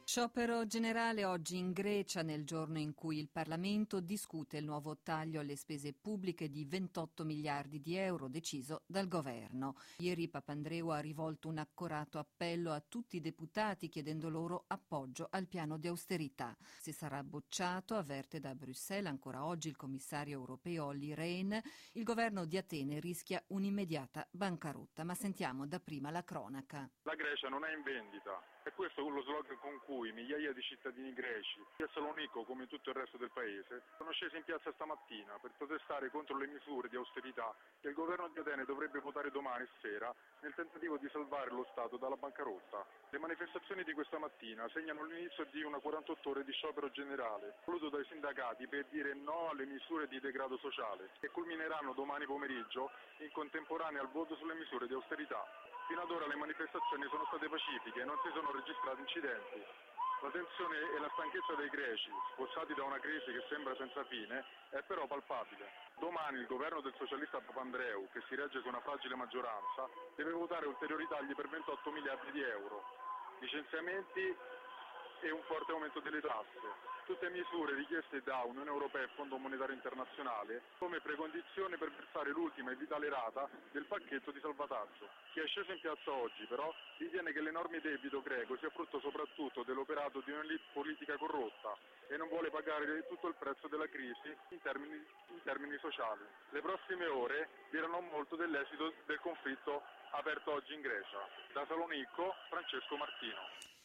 Inizia oggi in Grecia lo sciopero generale di 48 ore contro il pacchetto-austerità voluto dal governo Papandreou per salvare il paese dalla bancarotta. Il servizio dal Salonicco